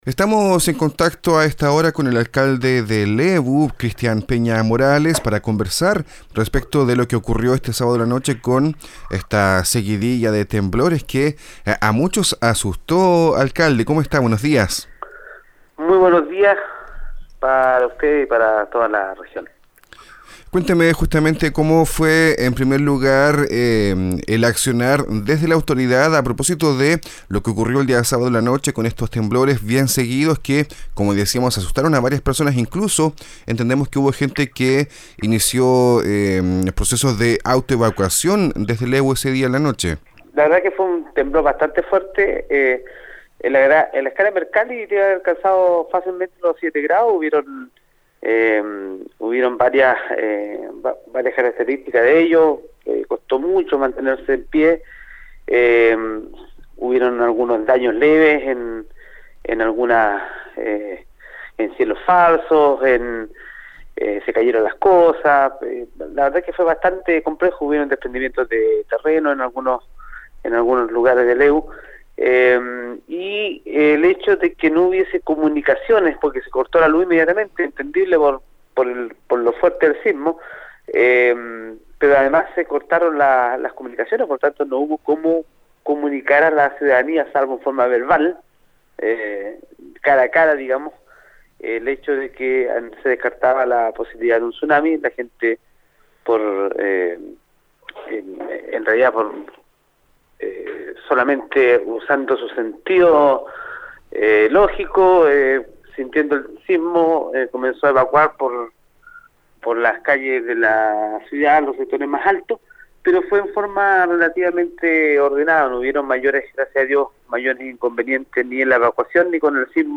En entrevista con Nuestra Pauta, el alcalde de Lebu, Cristian Peña Morales, se refirió a lo ocurrido en esa comuna tras los sismos registrados la noche de este sábado, precisamente con epicentro en ese lugar.